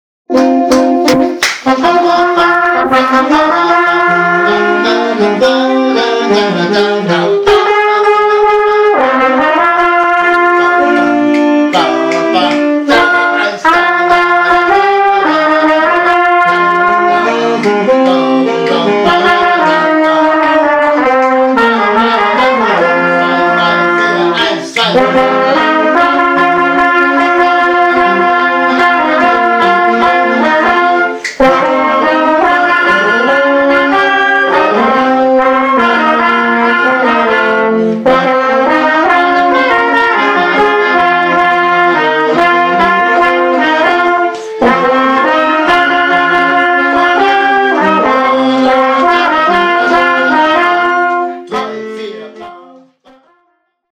Because I’m happy :) – Ein ganzes Wochenende lang Probe (ahhh)
Wochenend und Sonnenschein oder hartes Bandcamp? Beides bot das vergangene Probenwochenende für die Jugendlichen in der Jugendherberge in Murrhardt im Schwäbischen Wald.
Der Sound der Jugendkapelle
Jugendkapelle_HappyWilliams_Demo.mp3